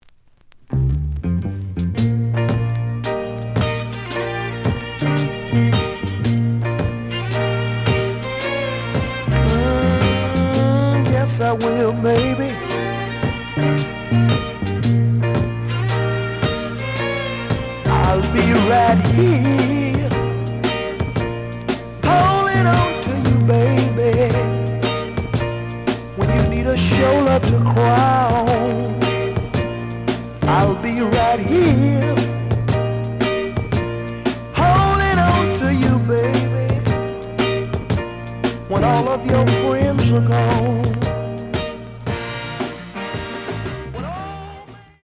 The audio sample is recorded from the actual item.
●Genre: Soul, 70's Soul
Slight noise on A side.